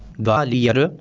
शृणु) /ˈɡvɑːlɪjərə/) (हिन्दी: ग्वालियर, आङ्ग्ल: Gwalior) इत्येतन्नगरं मध्यप्रदेशराज्यस्य ग्वालियरविभागे अन्तर्गतस्य ग्वालियरमण्डलस्य केन्द्रम् अस्ति ।